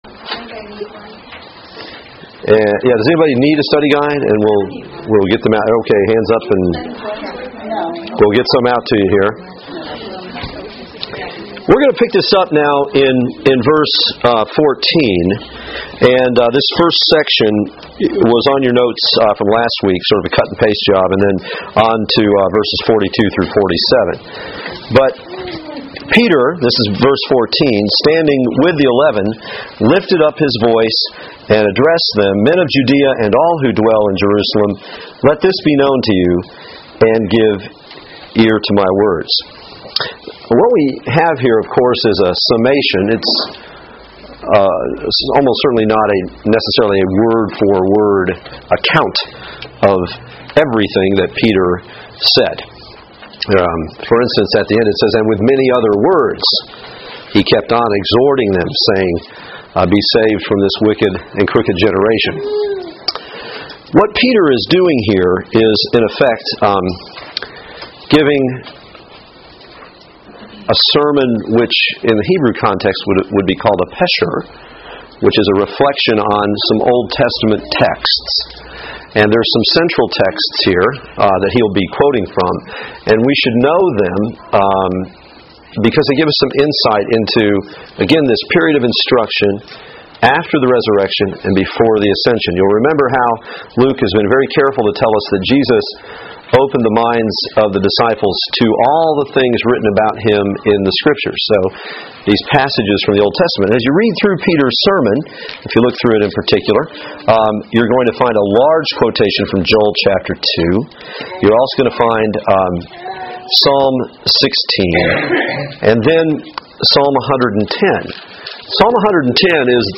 Passage: Acts 2:14-46 Service Type: Women's Bible Study